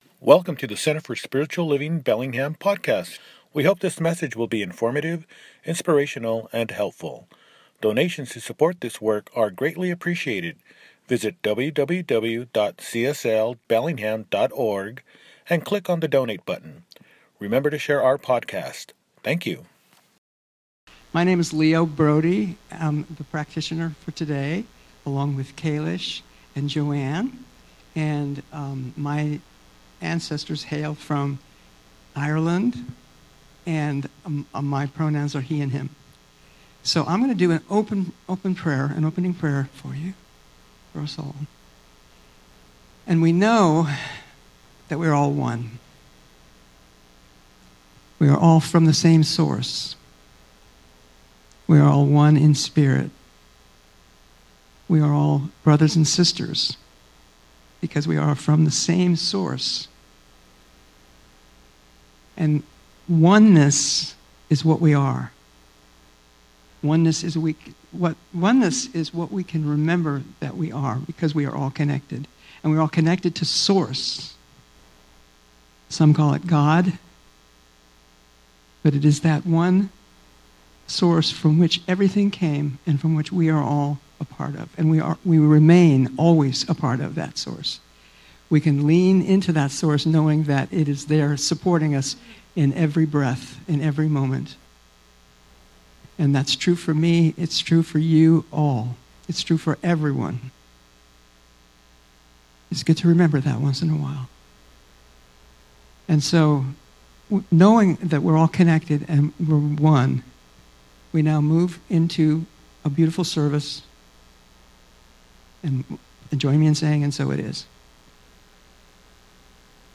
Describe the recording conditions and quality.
A World of Pure Imagination _ Second Service